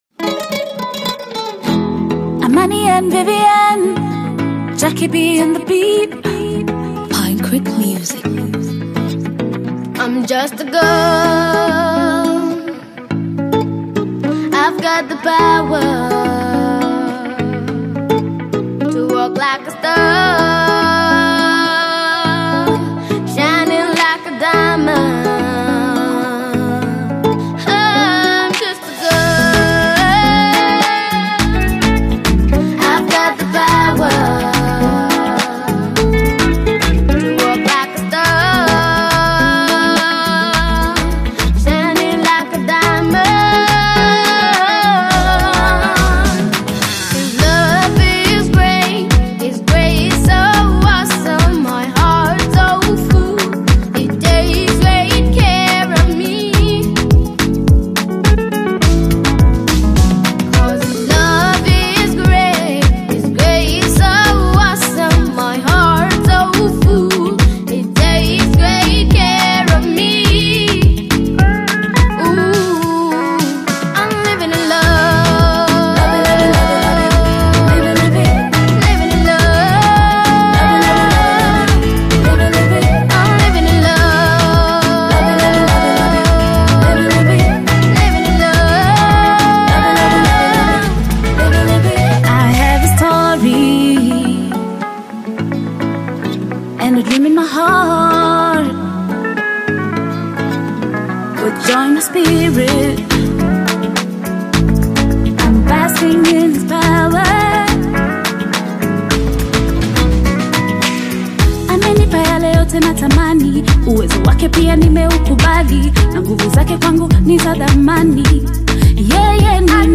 Genres: Electro, Funk, Pop